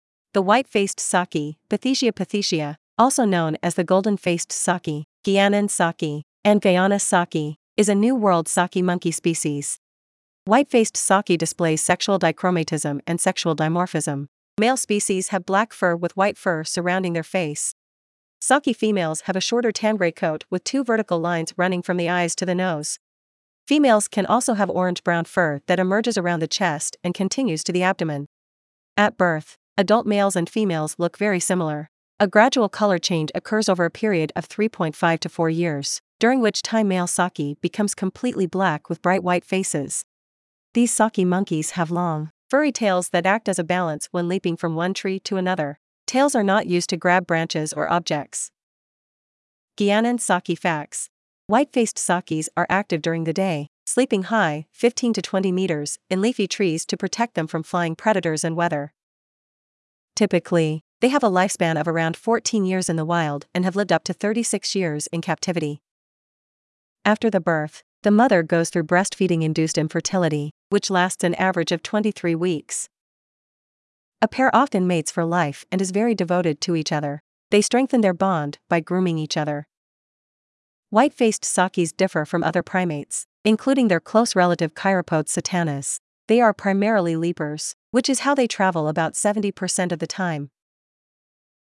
Guianan saki
Guianan-Saki.mp3